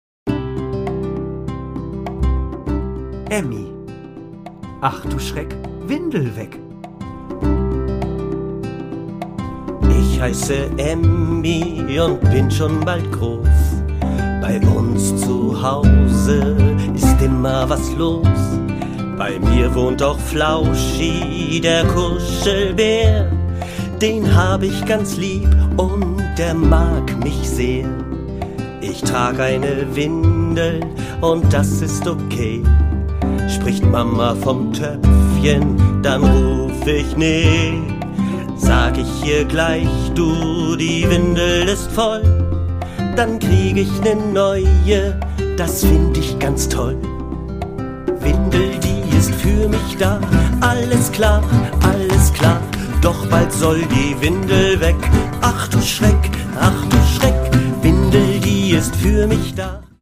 Produkttyp: Hörbuch-Download
Fassung: ungekürzte Fassung
Kurze Episoden und viele Lieder helfen beim Abschied von der Windel